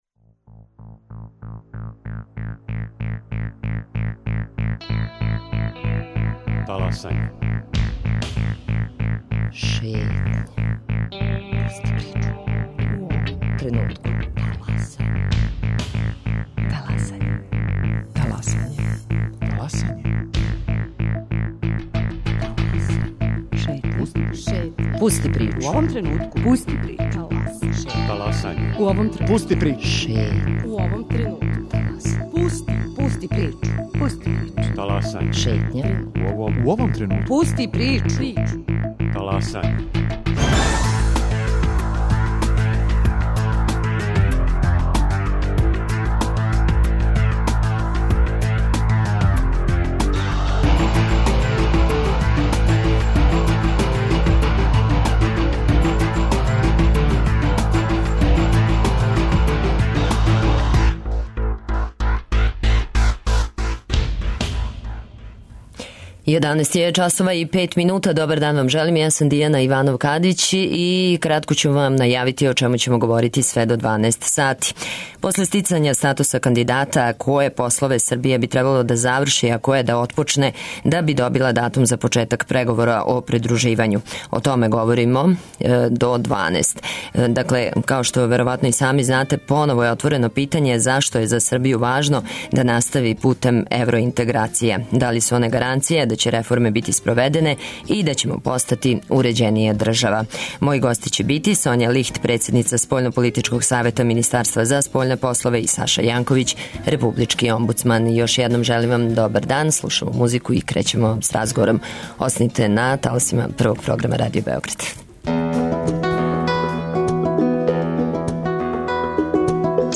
Гости емисије су Соња Лихт, председница Спољнополитичког савета Министарства за спољне послове и Саша Јанковић, републички омбудсман.